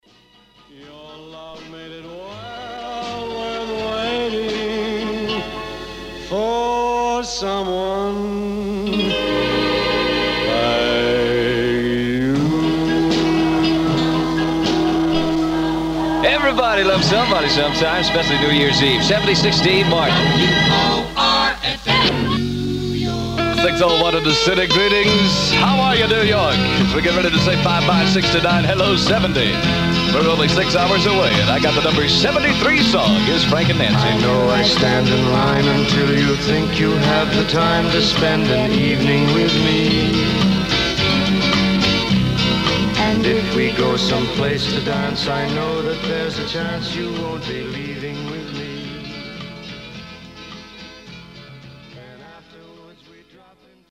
Na jednom z kotoučů jsem našel asi 50 minut záznamu vysílání populárního rádia té doby, AFN. Připomínám pouze, že to je stanice vysílající pro americké vojáky v Německu.
Tedy na krátké ukázce /asi 56 sec./ Uslyšíte konec jedné skladby, pak moderátora, jingle rádia, nájezd a moderátor jde do intra skladby následující, Something Stupid, tuším...   Končí asi 0,2 sec před nástupem zpěváka, když řekl, to to říci chtěl...